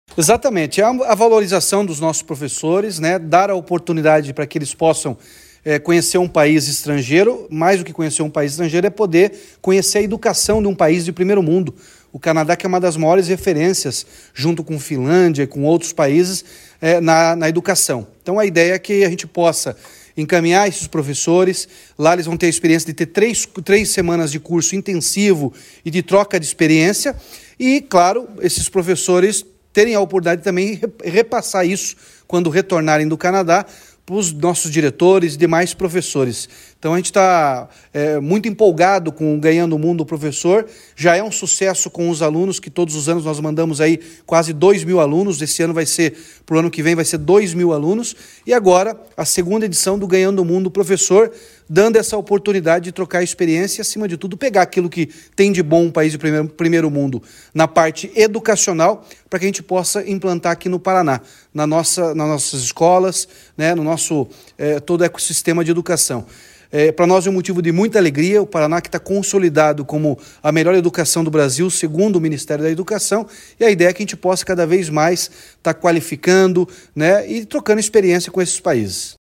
Sonora do governador Ratinho Junior sobre a segunda edição do programa Ganhado o Mundo Professor